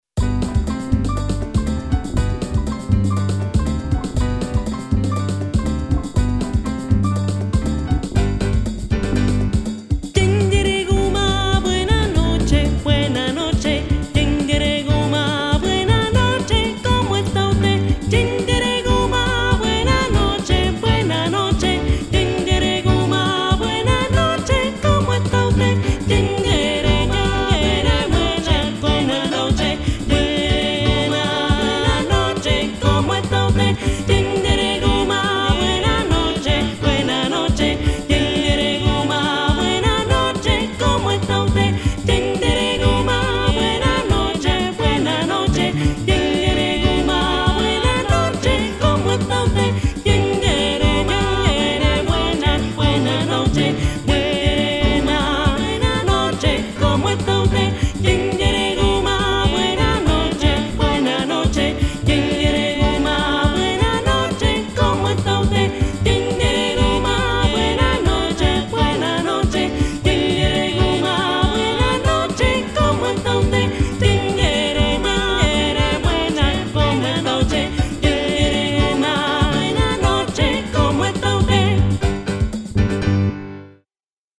Tradicional afrocubana